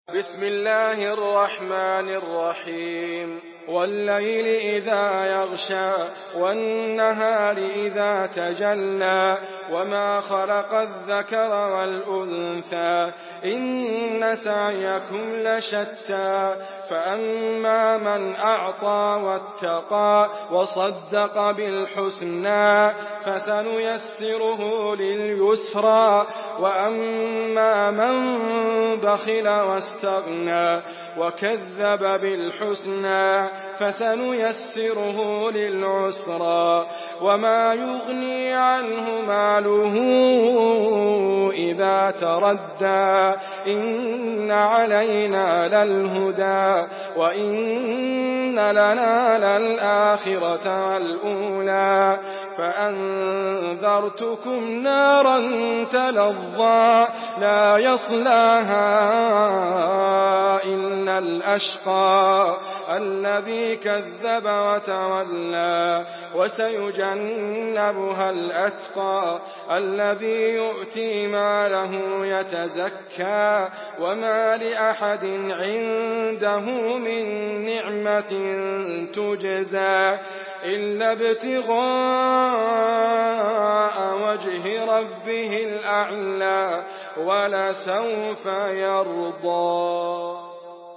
Surah Al-Layl MP3 in the Voice of Idriss Abkar in Hafs Narration
Surah Al-Layl MP3 by Idriss Abkar in Hafs An Asim narration. Listen and download the full recitation in MP3 format via direct and fast links in multiple qualities to your mobile phone.